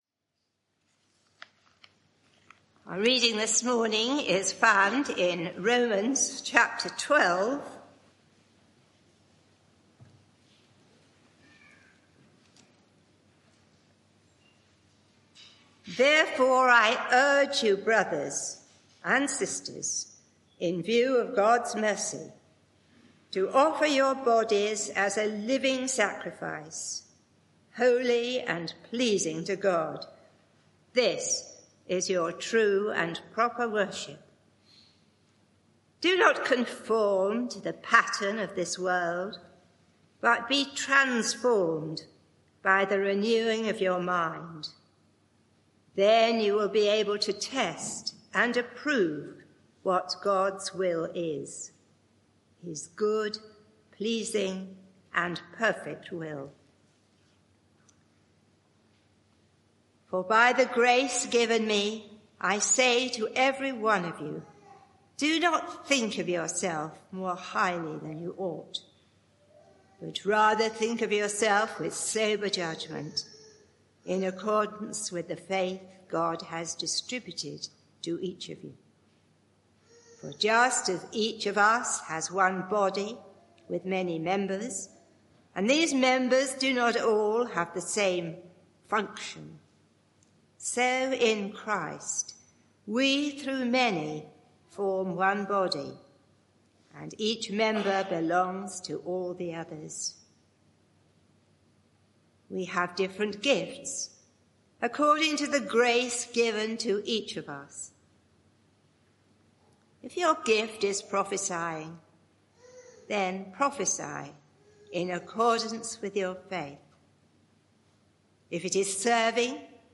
Media for 11am Service on Sun 20th Oct 2024 11:00 Speaker
Sermon (audio)